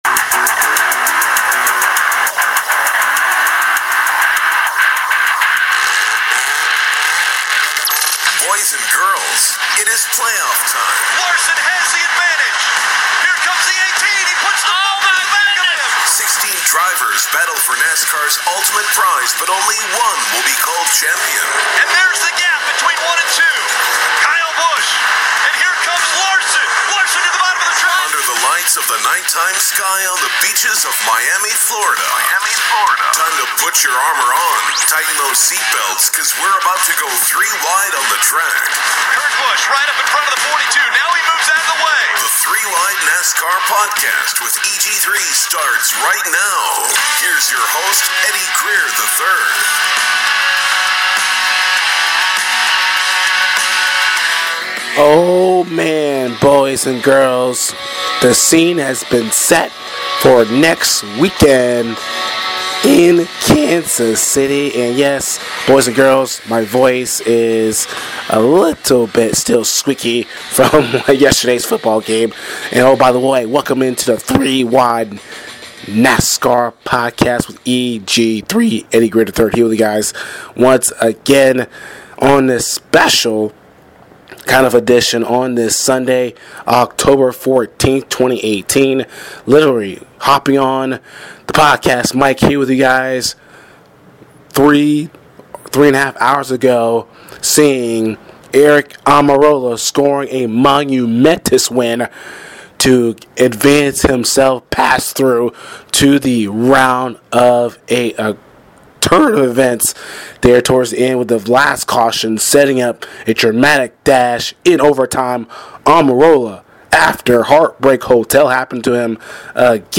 a hoarse voice from football